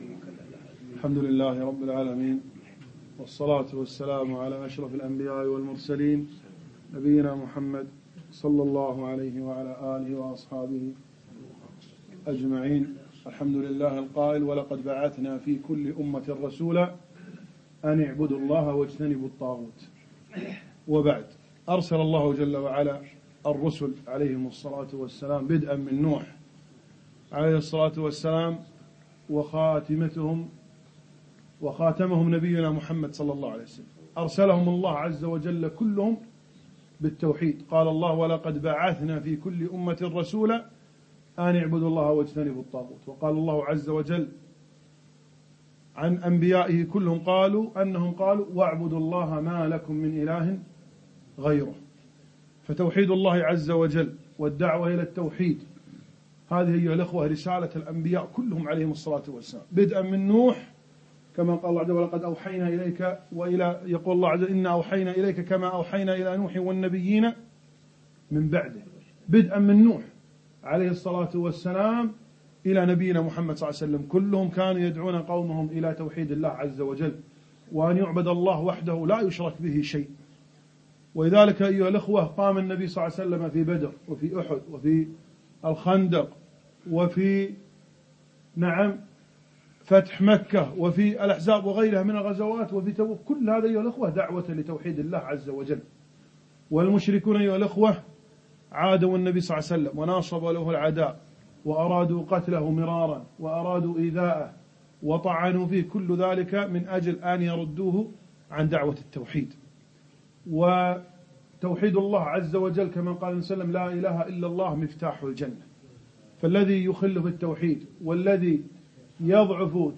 كل الأنبياء دعوتهم التوحيد ... كلمة